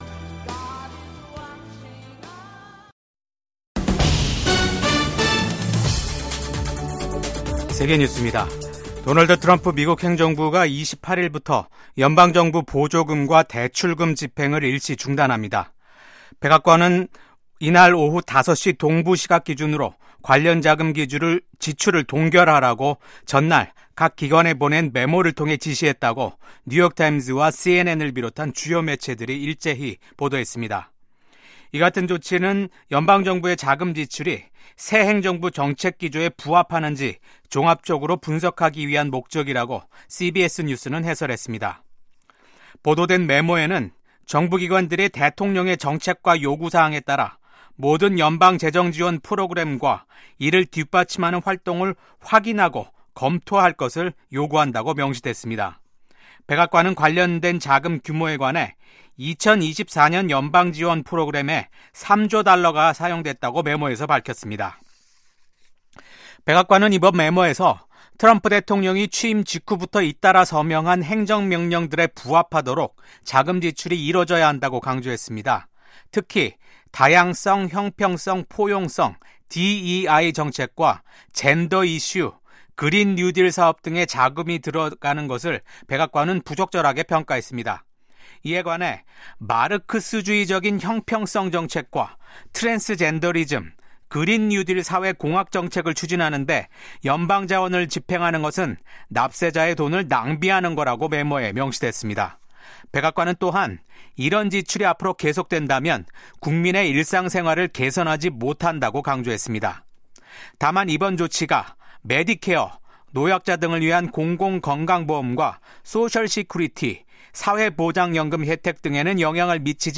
VOA 한국어 아침 뉴스 프로그램 '워싱턴 뉴스 광장'입니다. 한국과 쿠바 두 나라는 수교 11개월만에 대사관 개설과 대사 부임 절차를 마무리했습니다. 트럼프 대통령이 북한을 ‘핵 보유국’이라고 지칭한 이후 한국 내에서 커지고 있는 자체 핵무장론과 관련해 미국 전문가들은 미한동맹과 역내 안정에 부정적인 영향을 미칠 것이라고 전망했습니다.